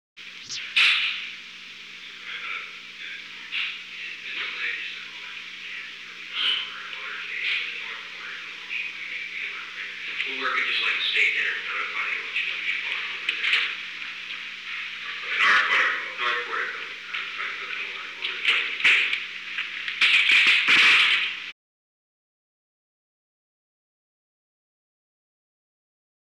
Secret White House Tapes
Conversation No. 946-15
Location: Oval Office
The President met with an unknown man.